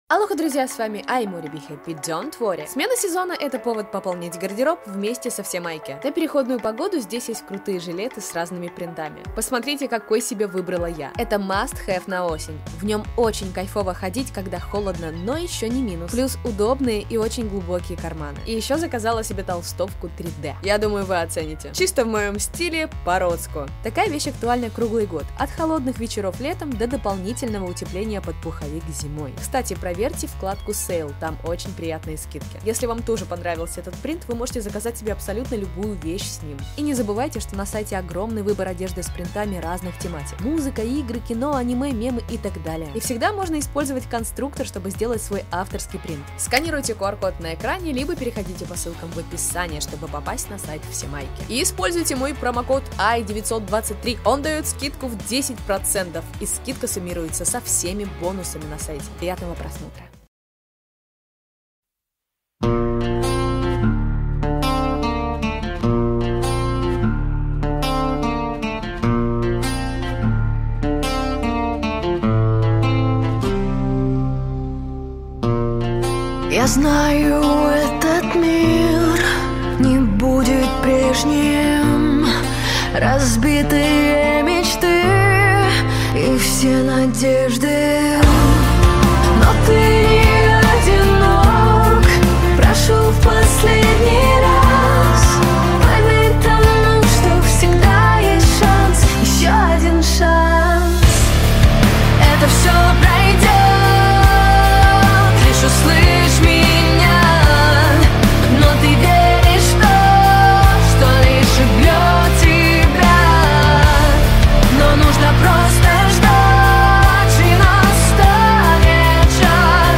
1:01 - Кавер